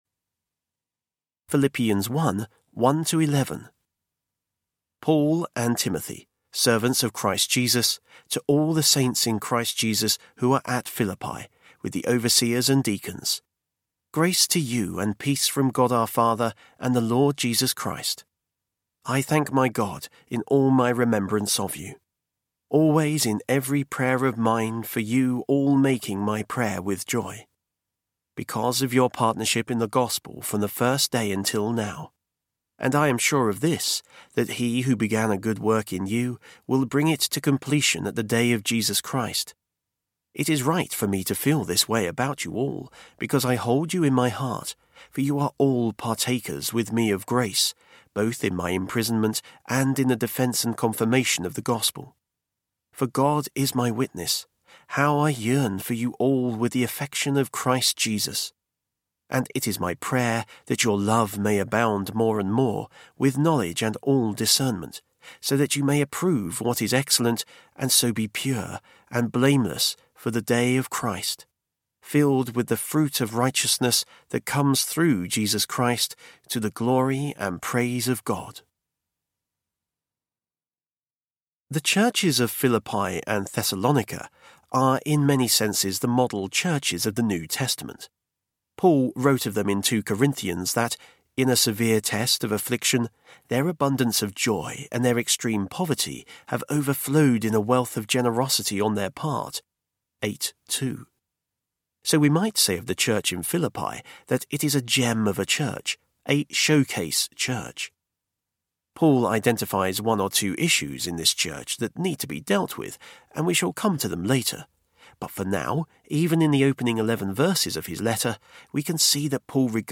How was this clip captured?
5.1 Hrs. – Unabridged